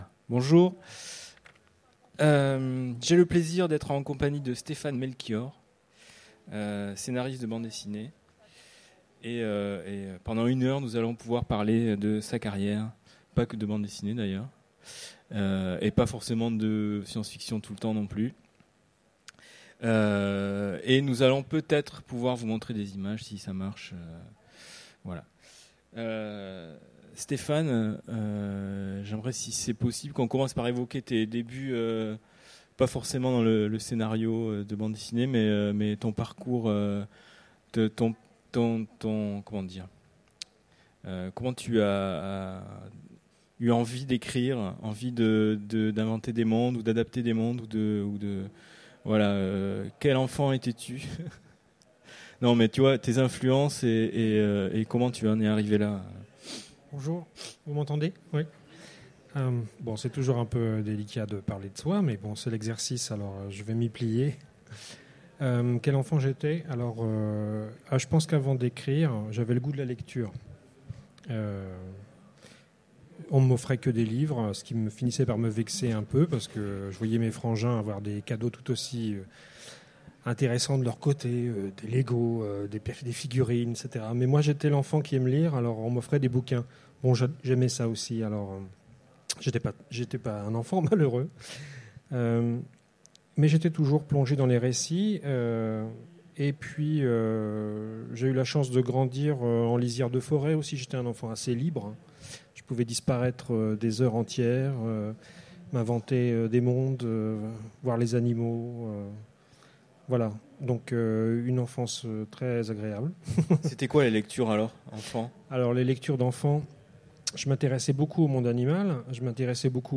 Utopiales 2015
Mots-clés Rencontre avec un auteur Conférence Partager cet article